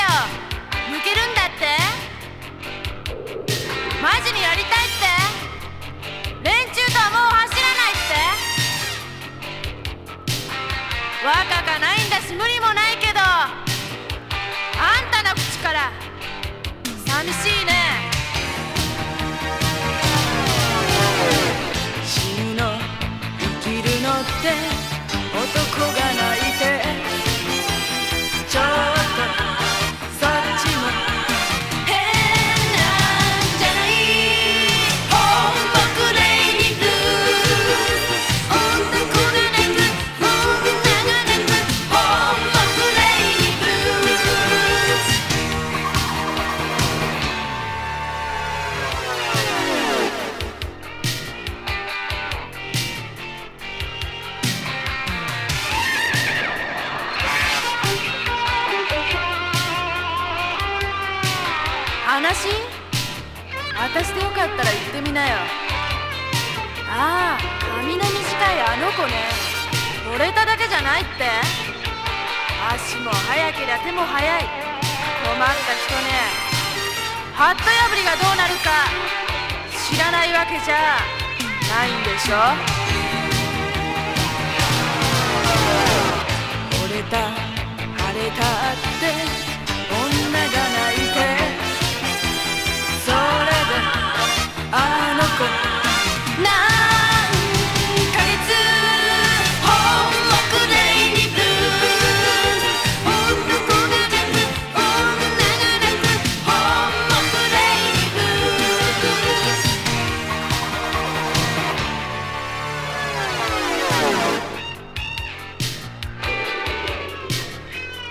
イントロの台詞がキマッてる！
Japanese 80's ロック / ポップス